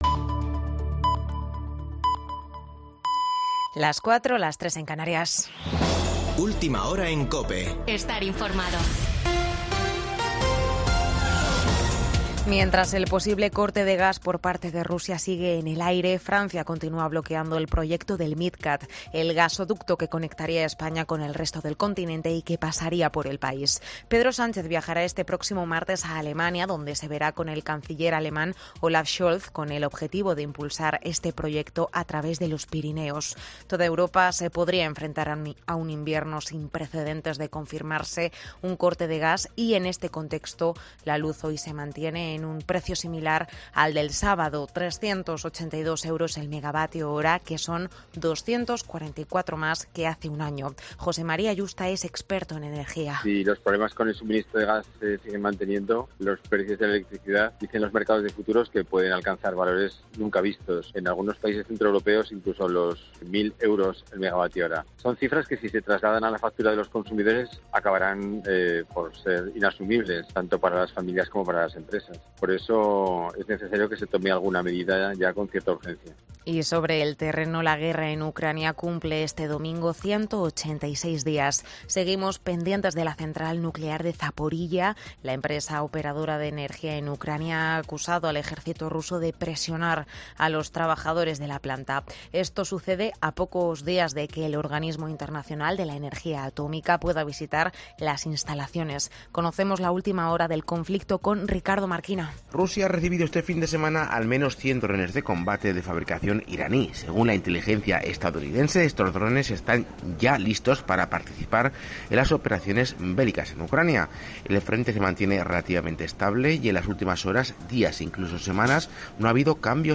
Boletín de noticias de COPE del 28 de agosto de 2022 a las 04.00 horas